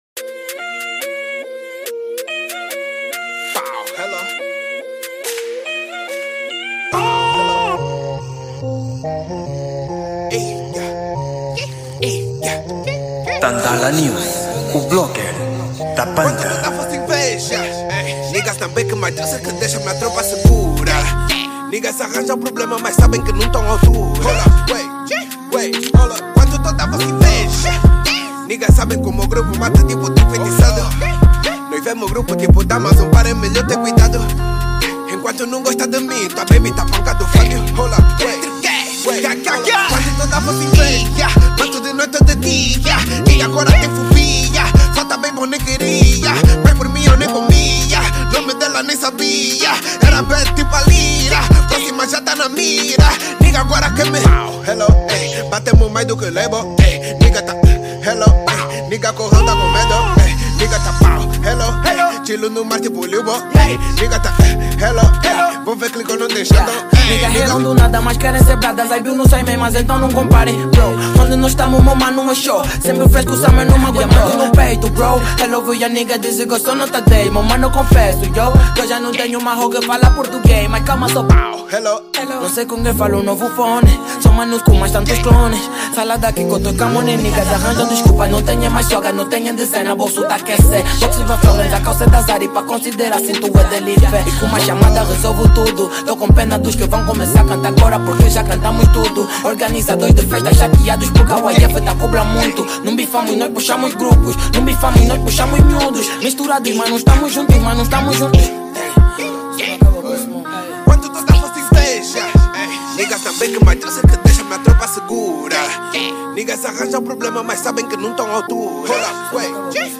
Gênero: Trap